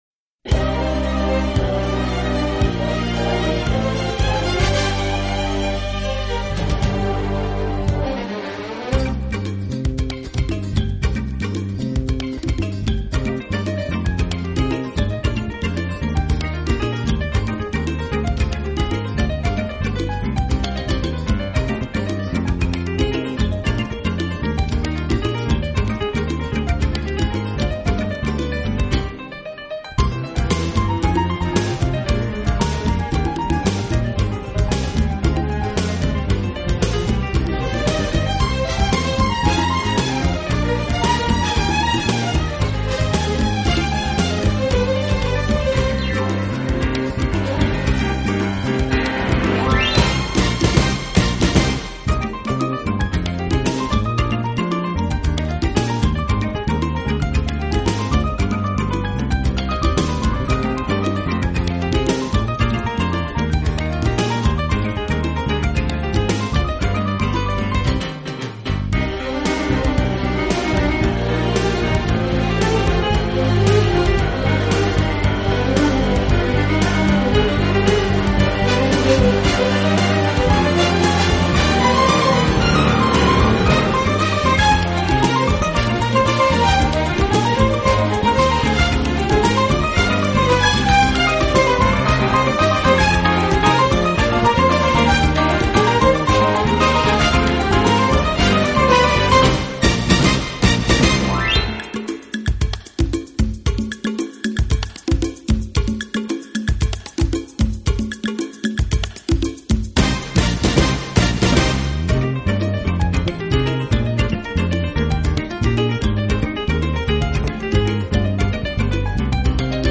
【拉丁钢琴】